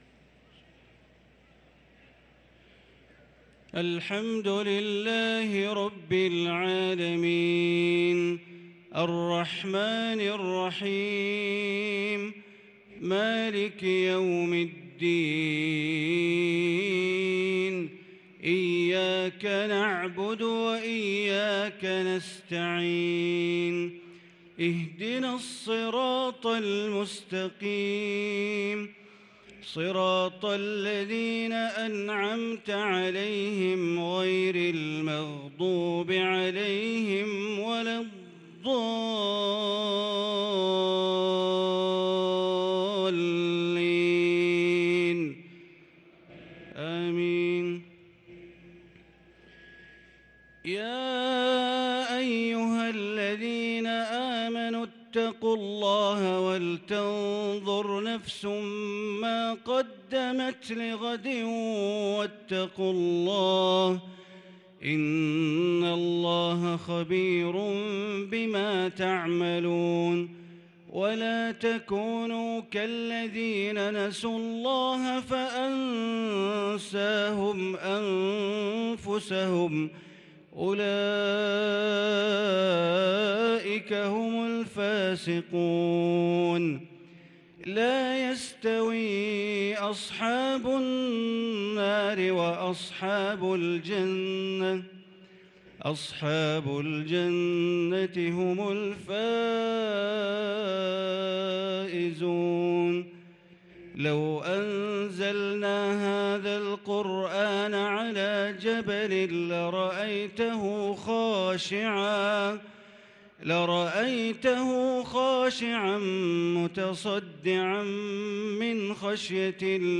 صلاة العشاء للقارئ بندر بليلة 8 رمضان 1443 هـ
تِلَاوَات الْحَرَمَيْن .